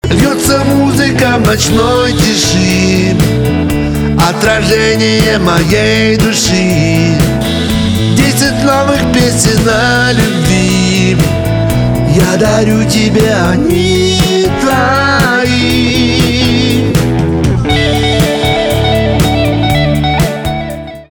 русский рок
романтические , гитара , барабаны
чувственные